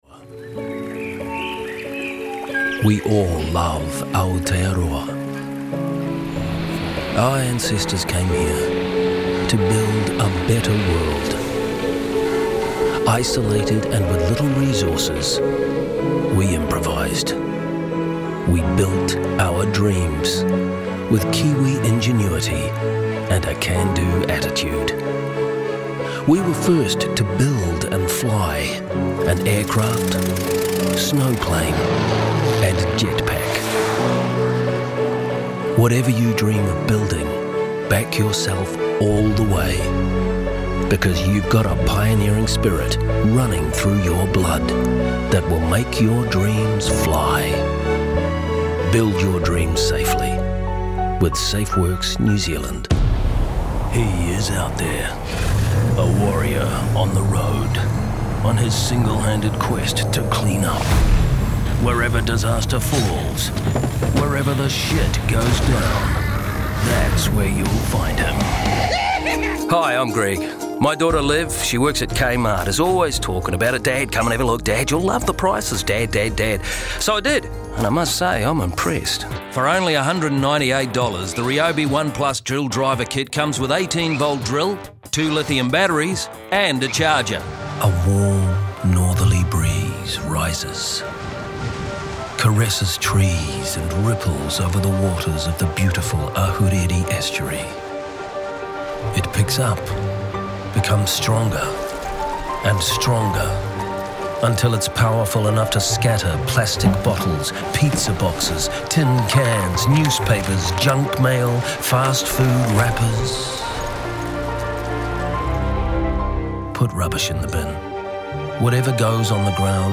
Demo
Adult
international english
new zealand | natural
documentary